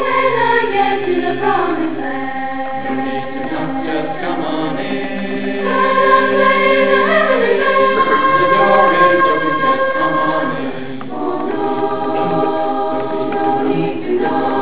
South Whidbey High School
Concert Choir